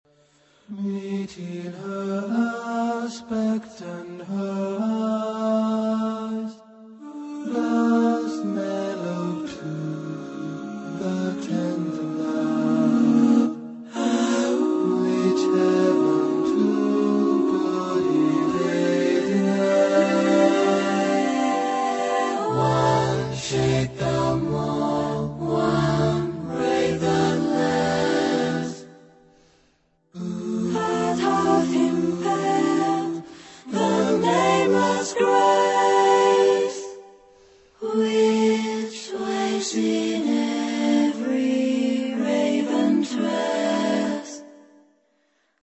Genre-Style-Forme : Profane ; Jazz vocal ; Close Harmony
Caractère de la pièce : belles sonorités ; tendre ; doux
Type de choeur : SATB  (4 voix mixtes )
Tonalité : accords de jazz